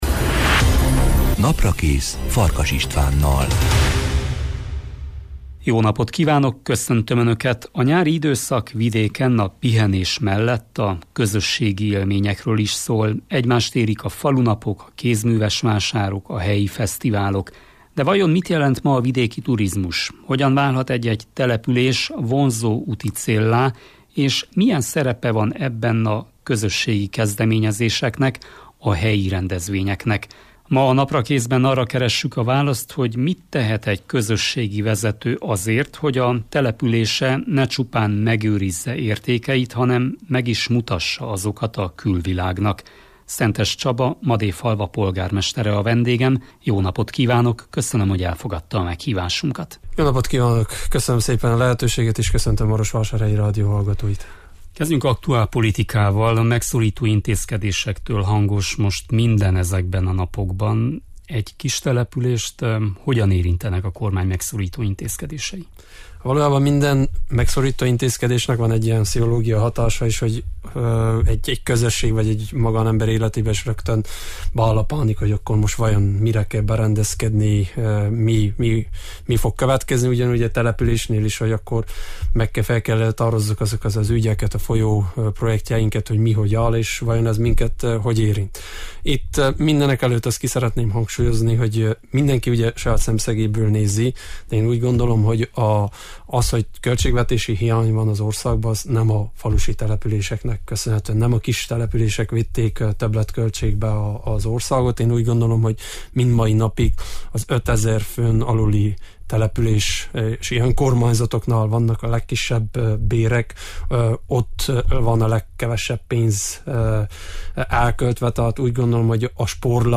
Ma arra keressük a választ, mit tehet egy közösségi vezető azért, hogy települése ne csupán megőrizze értékeit, hanem meg is mutassa azokat a külvilágnak. Szentes Csaba, Madéfalva polgármestere a Naprakész vendége.